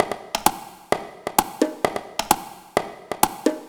130_bongo_2.wav